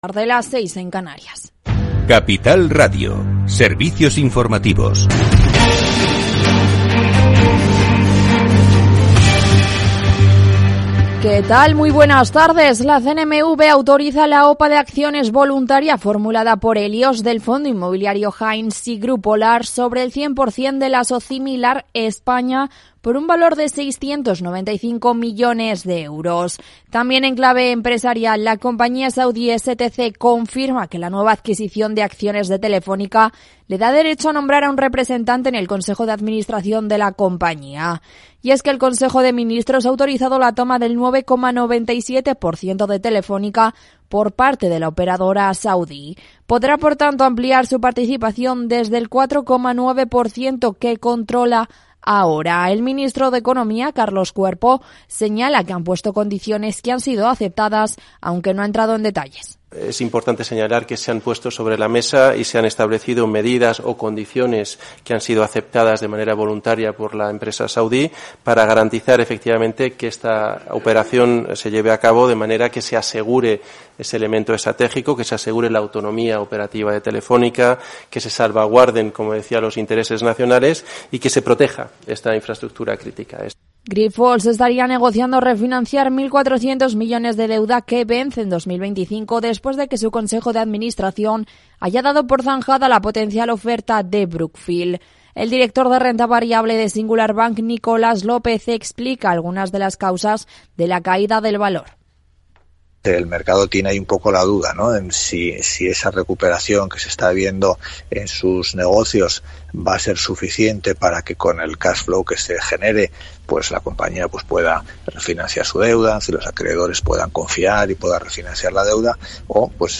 Programa diario dedicado a las pymes, profesionales, autónomos y emprendedores. Hablamos de Big Data y de crowdfunding, de management y coaching, de exportar e importar, de pedir créditos a los bancos y de empresas fintech. Los especialistas comparten sus experiencias, sus casos de éxito y sus fracasos. Todo en un tono propio de un Afterwork, en el que podrás hacer un poco de networking y hacer negocios más allá de la oficina.